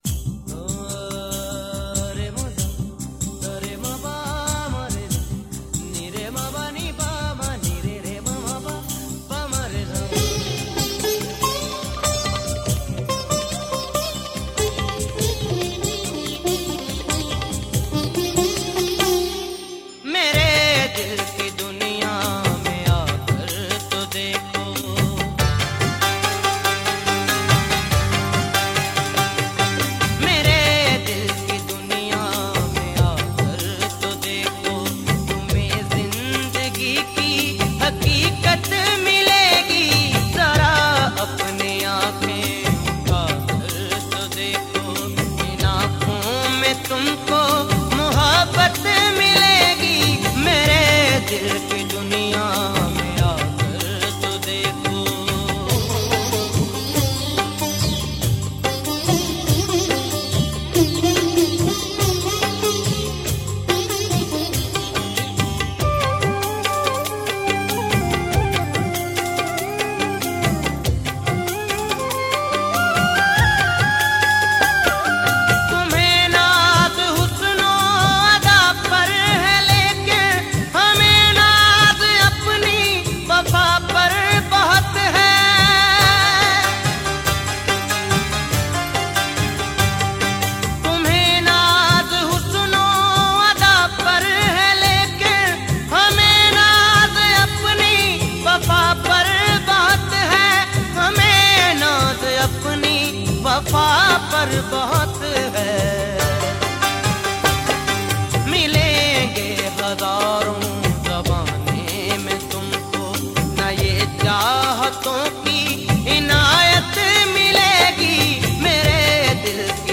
Punjabi Qawwali and Sufiana Kalam
Urdu ghazal